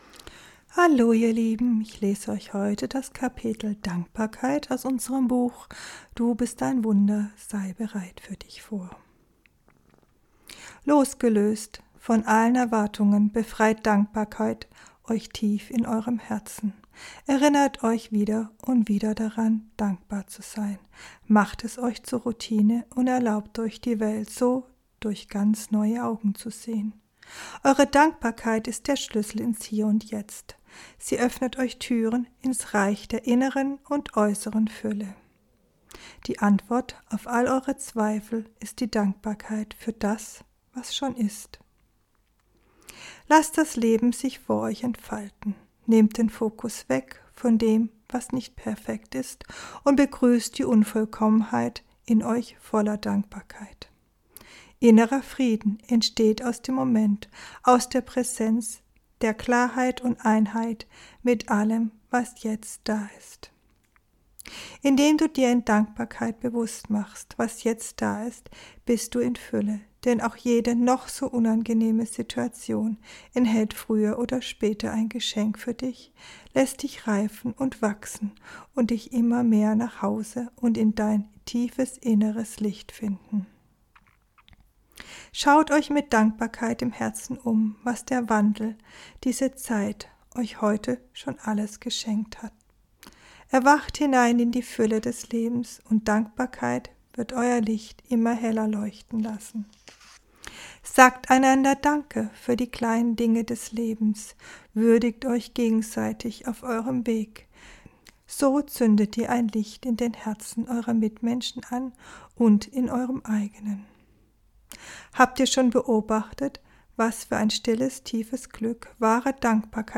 Ich lese dir vor.